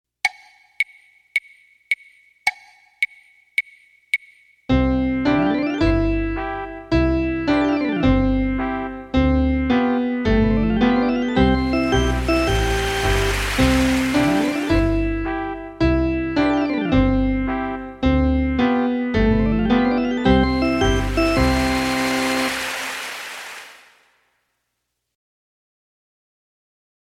Voicing: Piano Method w/ Audio